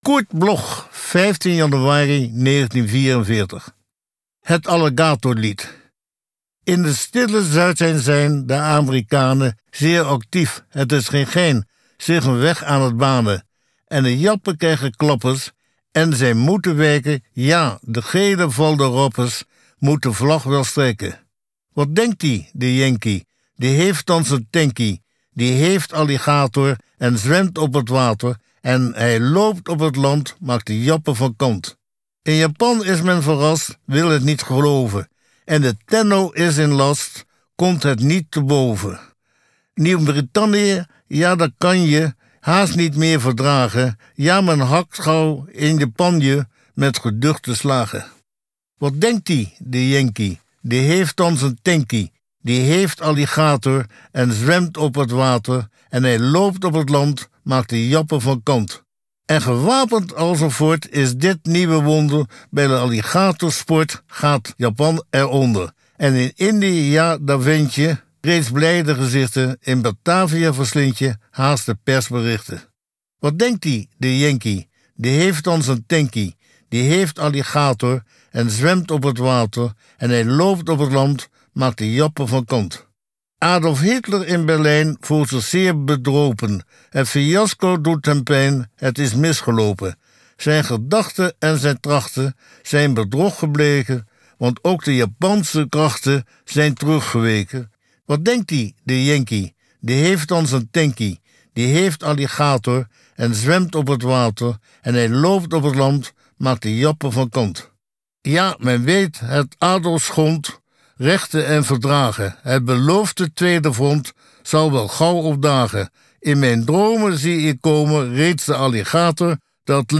Recording: MOST, Amsterdam · Editing: Kristen & Schmidt, Wiesbaden